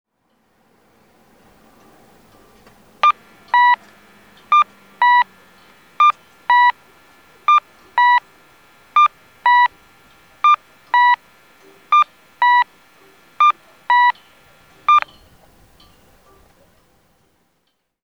佐伯駅先(大分県佐伯市)の音響信号を紹介しています。